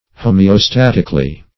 Meaning of homeostatically. homeostatically synonyms, pronunciation, spelling and more from Free Dictionary.
homeostatically.mp3